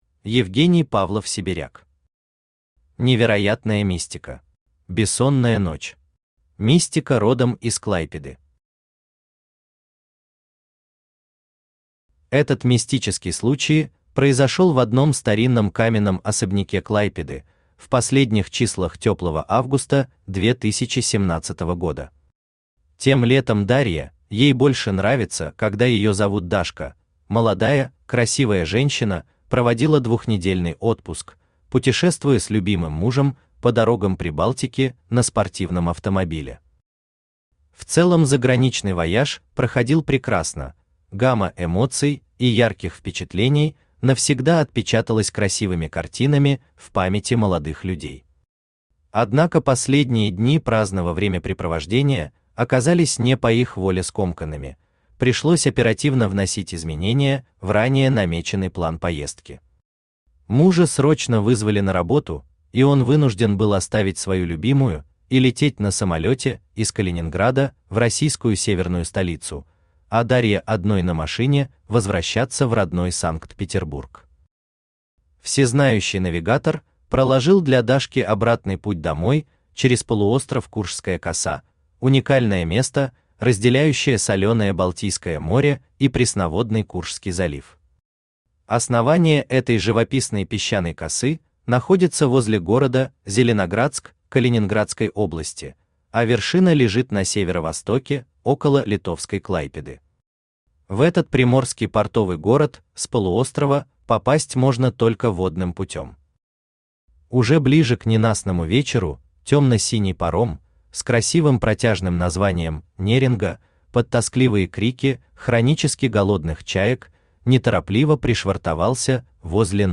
Аудиокнига Невероятная мистика | Библиотека аудиокниг
Aудиокнига Невероятная мистика Автор Евгений Павлов-Сибиряк Читает аудиокнигу Авточтец ЛитРес.